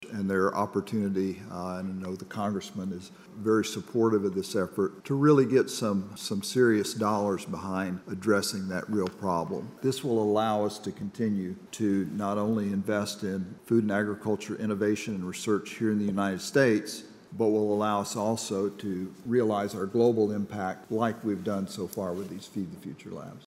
A panel discussion, hosted by Farm Journal Foundation and Kansas State University Tuesday at the Stanley Stout Center, focused on how agricultural innovations can mitigate global hunger and malnutrition.